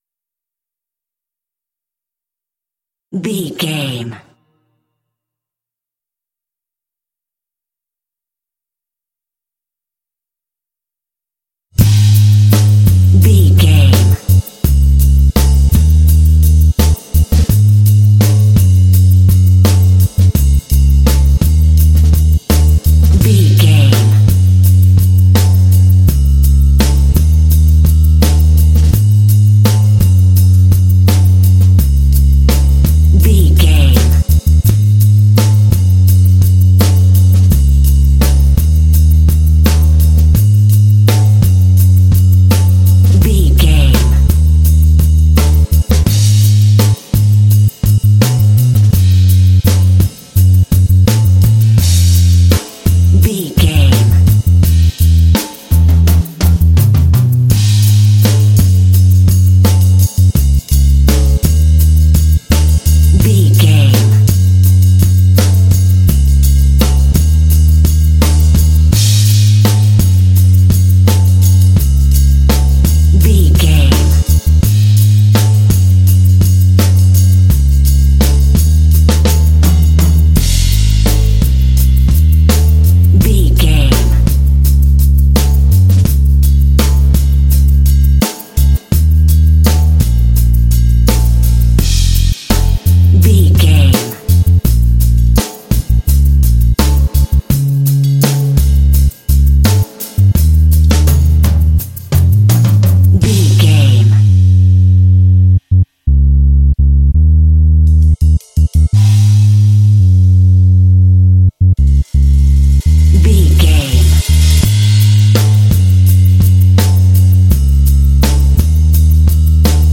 Relaxed, easy track, ideal for adventure games.
Mixolydian
A♭
relaxed
serene
mellow
soft
drums
bass guitar
contemporary underscore